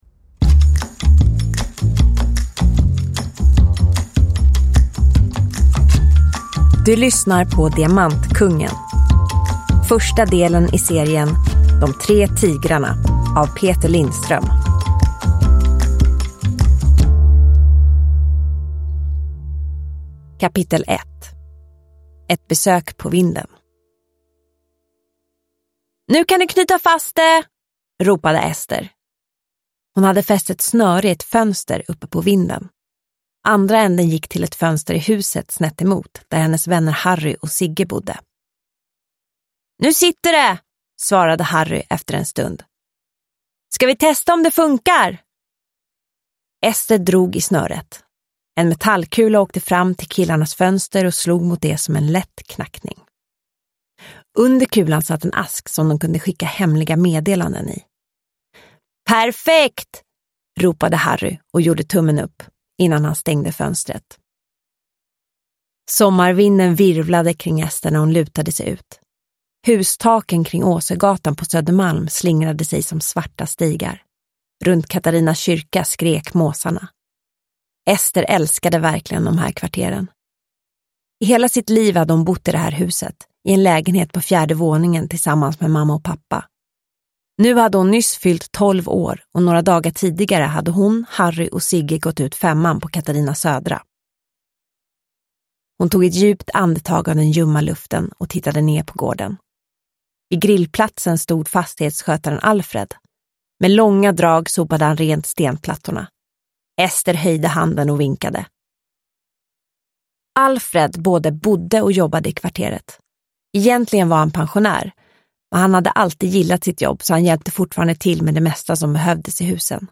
Diamantkungen – Ljudbok – Laddas ner